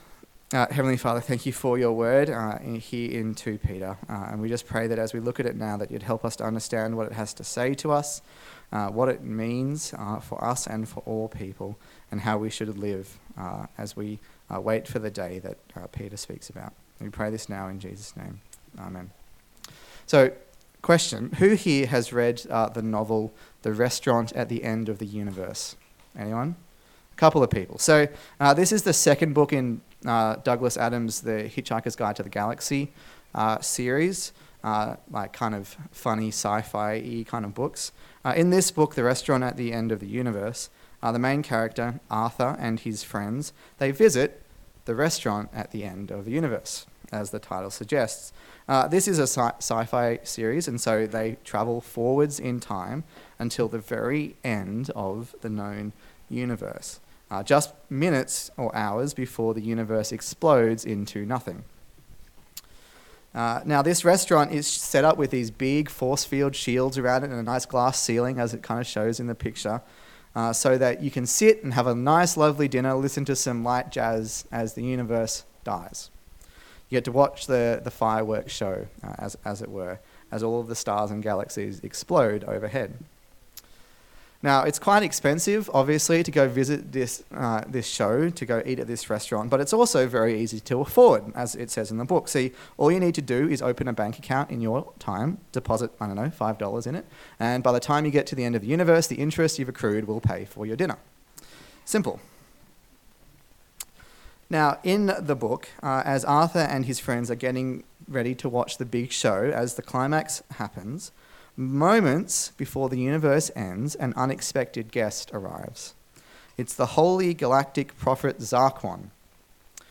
A sermon in the series on the letter 2 Peter
Service Type: Sunday Service